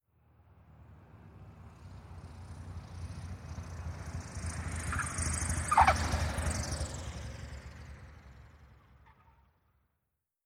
jetLand.wav